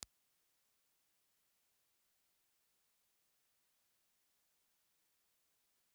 Impulse Response file of a Lustraphone VR65 stereo ribbon microphone (channel 1)
LustraphoneVR65_Stereo.wav